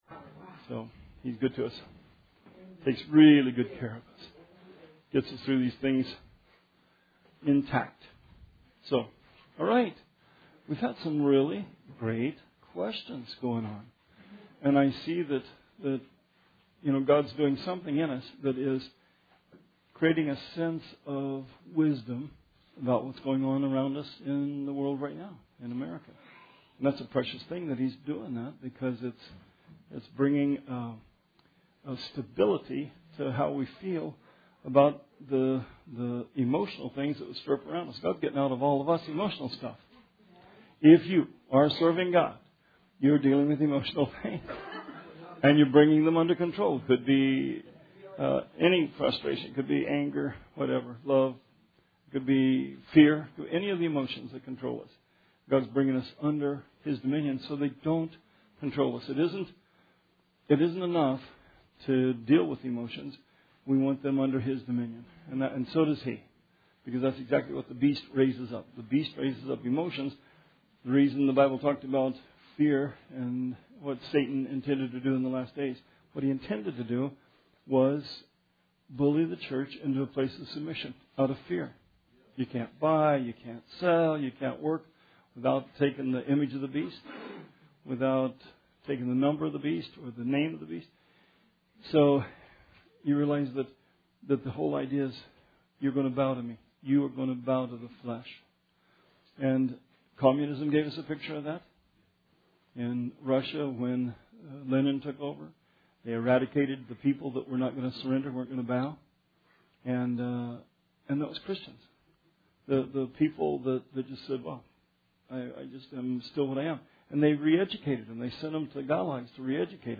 Bible Study 2/15/17